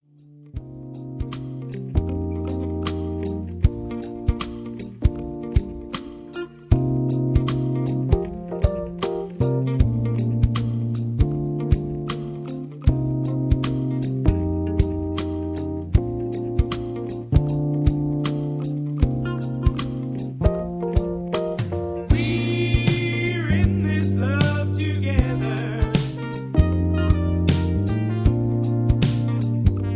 Key-G